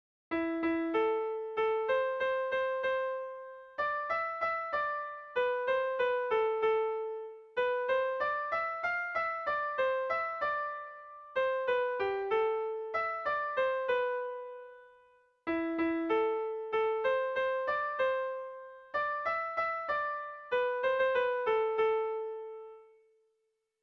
Sentimenduzkoa
Larraine < Basabürüa < Zuberoa < Euskal Herria
ABBAB